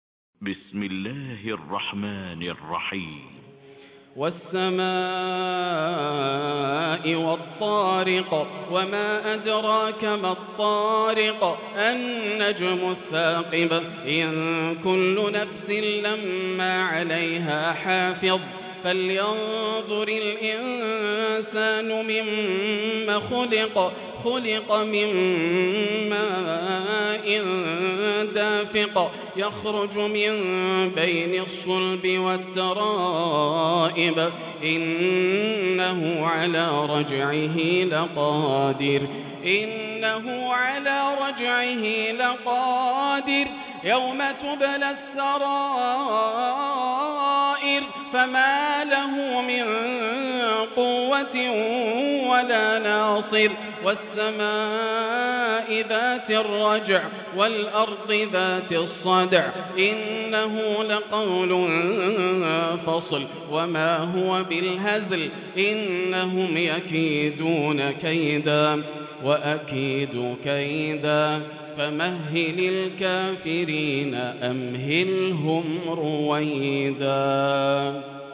Quran recitations
Tarawih prayer from the holy Mosque